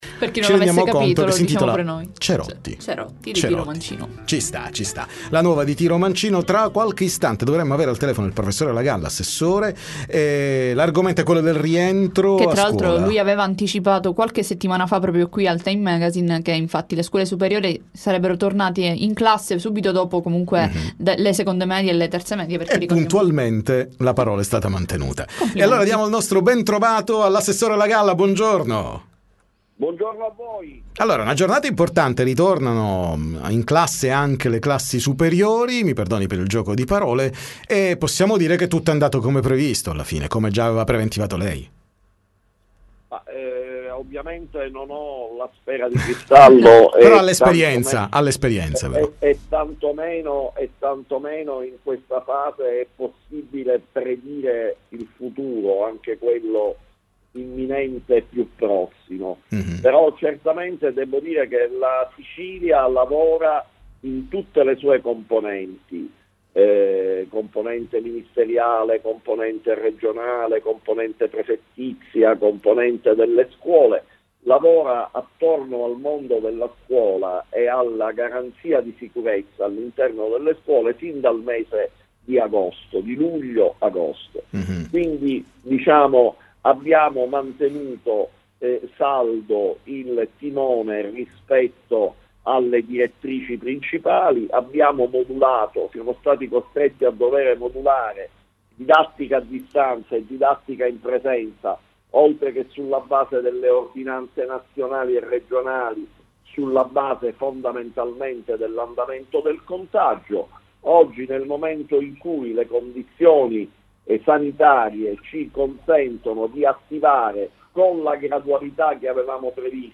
TM intervista l’ass. Roberto Lagalla